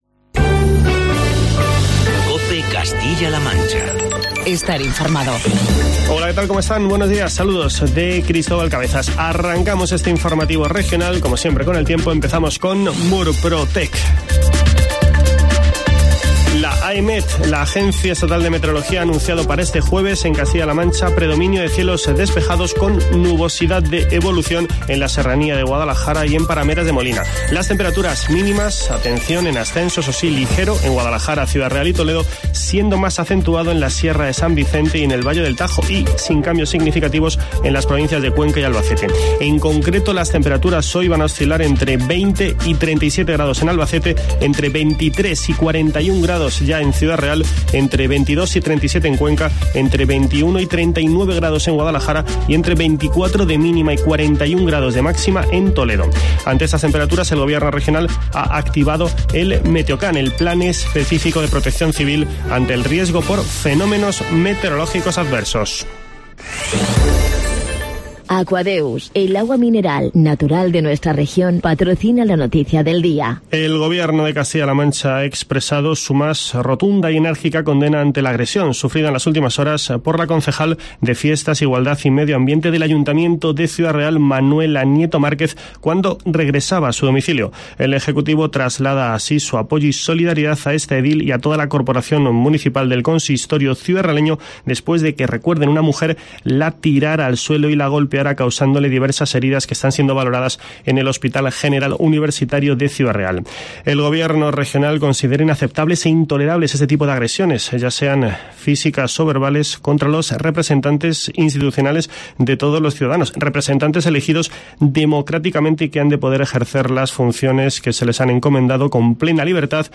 Informativo matinal de COPE Castilla-La Mancha.